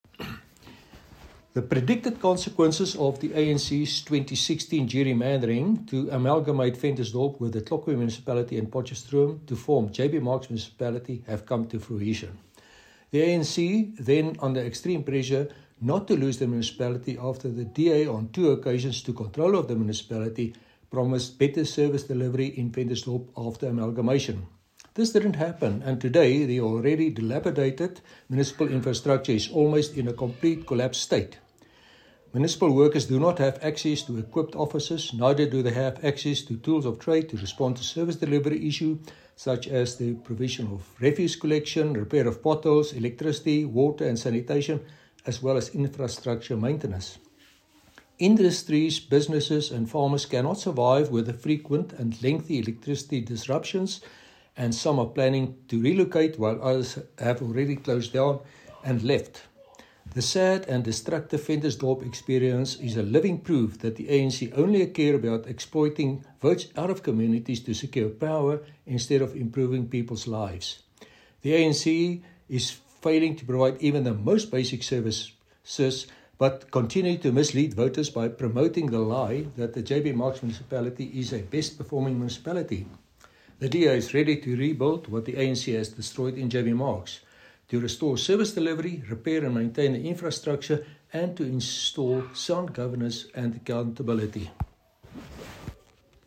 Note to Editors: Please find attached soundbites in
Afrikaans by Cllr Chris Hattingh.